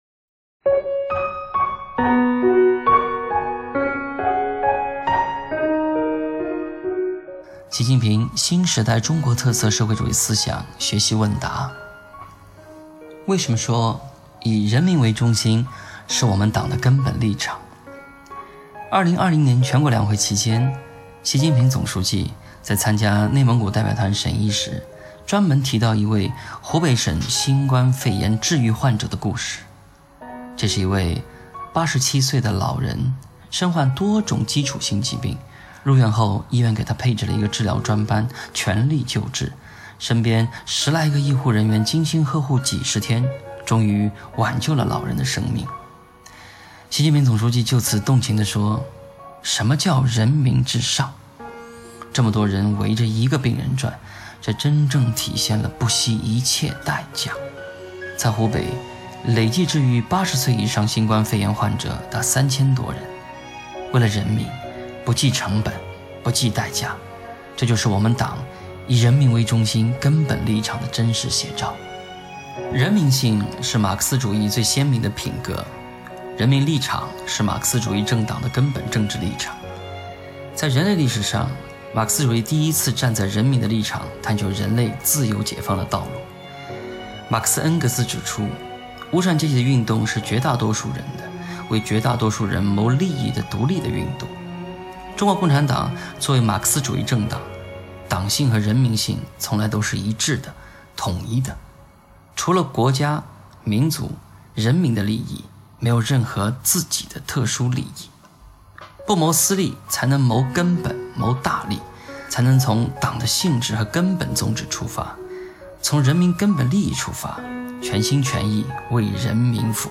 朗读者
录音、配乐